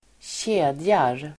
Uttal: [²tj'e:djar]